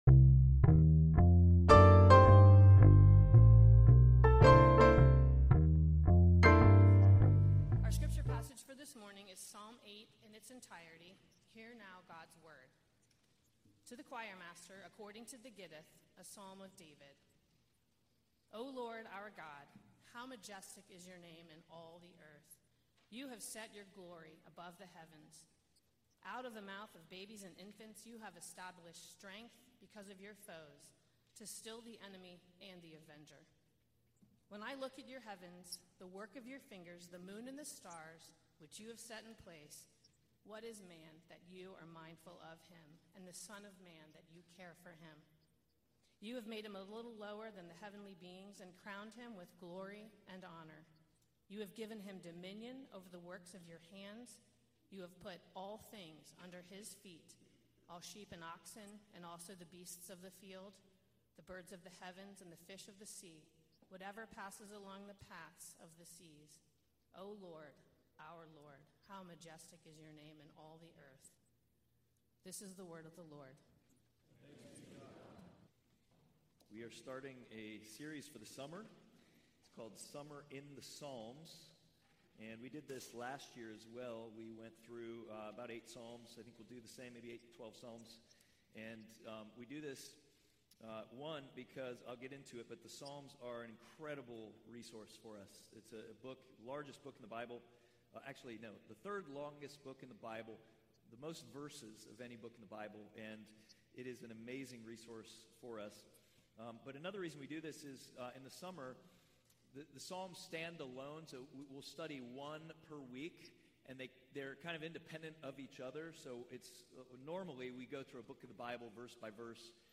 Passage: Psalm 8 Service Type: Sunday Worship « Bet Your Life Summer In The Psalms
NAPC_Sermon_6.2.24.mp3